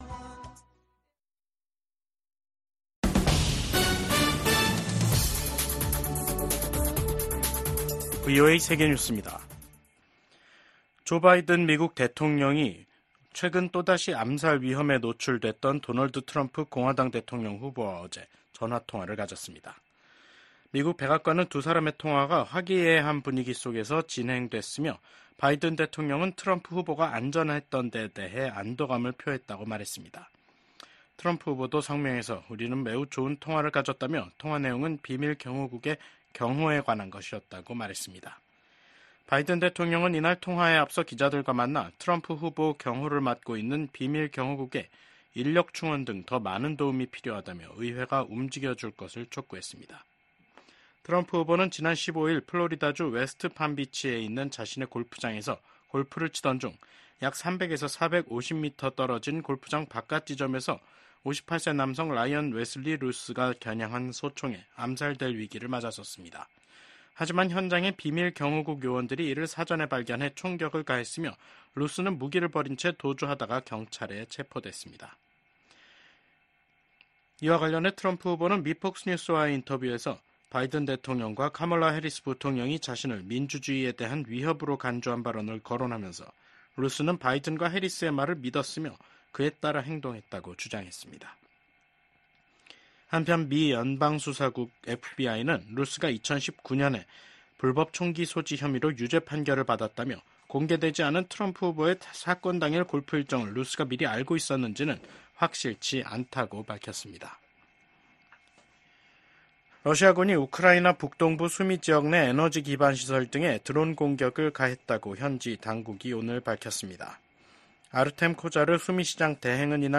VOA 한국어 간판 뉴스 프로그램 '뉴스 투데이', 2024년 9월 17일 3부 방송입니다. 스웨덴이 신종 코로나에 따른 북한의 국경 봉쇄 조치 이후 서방 국가로는 처음으로 외교관들을 북한에 복귀시켰습니다. 국제원자력기구(IAEA) 총회가 개막한 가운데 북한의 지속적인 핵 개발은 명백한 유엔 안보리 결의 위반이라고 IAEA 사무총장이 지적했습니다.